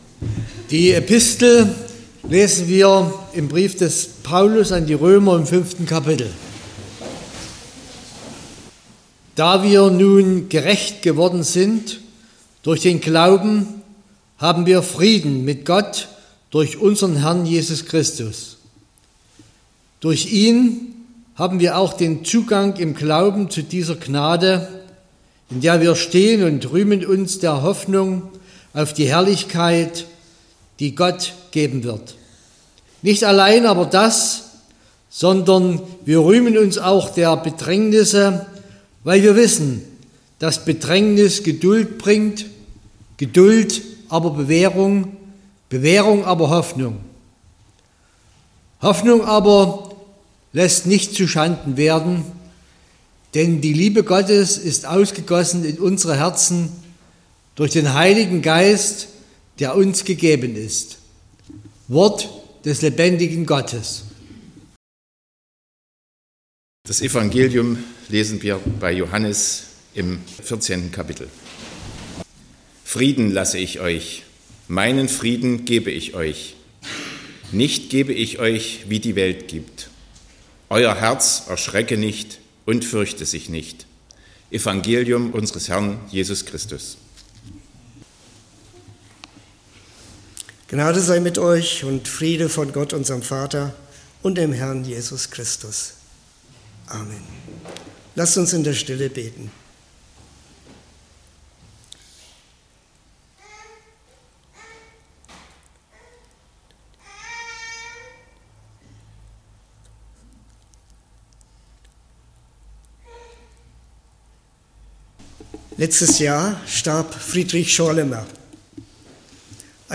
23.02.2025 – Gottesdienst
Predigt und Aufzeichnungen